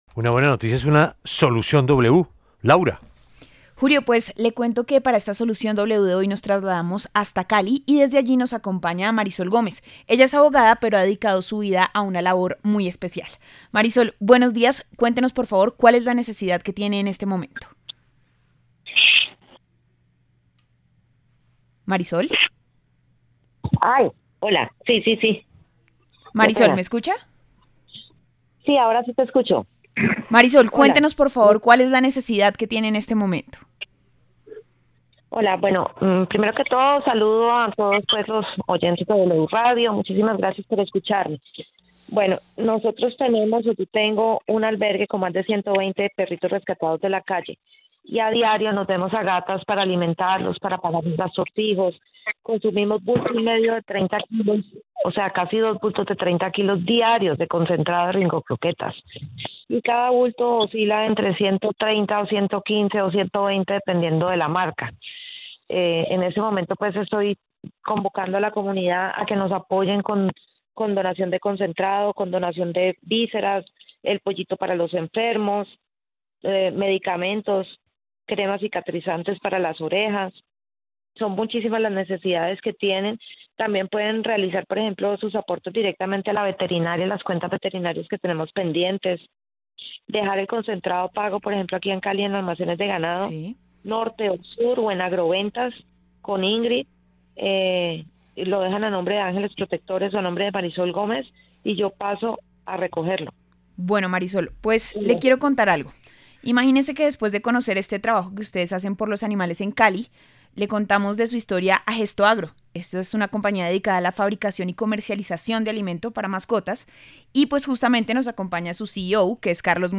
“Uno no le puede decir al animalito ‘no tengo para darte de comer’. Esto es todos los días, sin falta”, dijo con la voz quebrada al aire en W Radio, mientras contaba las múltiples necesidades que enfrenta: alimento, medicamentos, vísceras, cremas cicatrizantes y deudas en veterinarias.